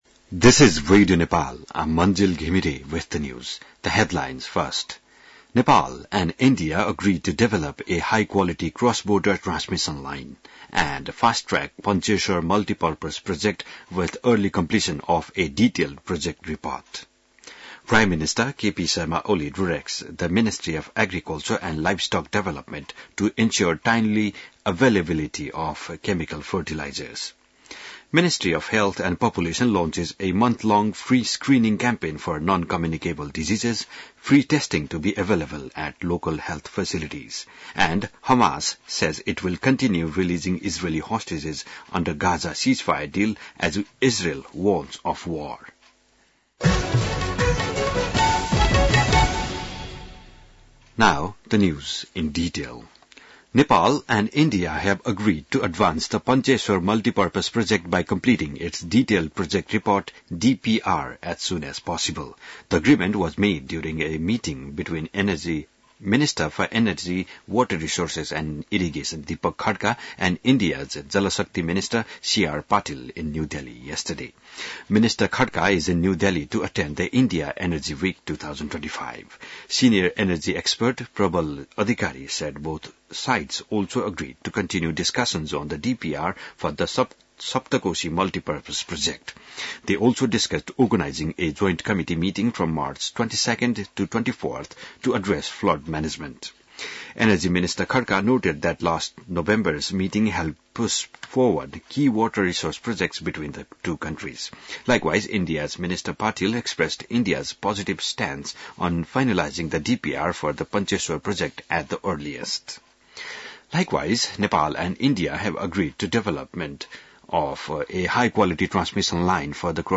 बिहान ८ बजेको अङ्ग्रेजी समाचार : ३ फागुन , २०८१